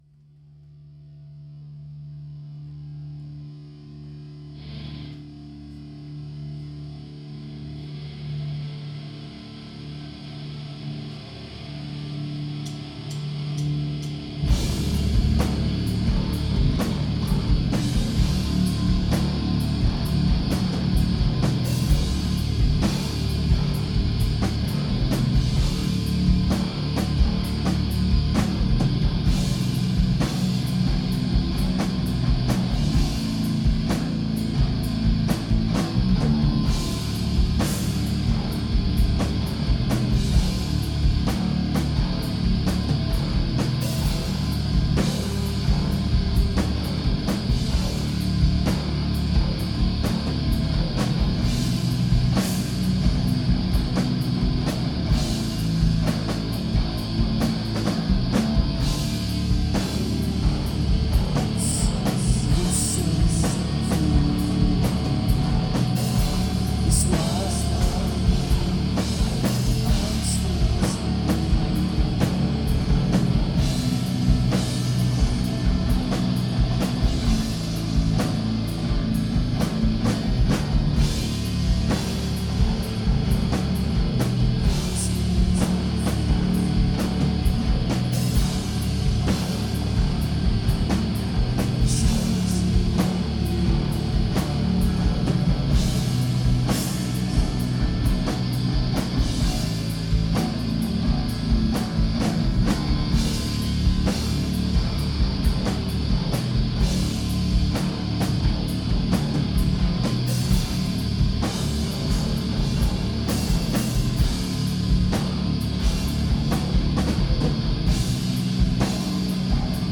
Some tapes by me and other people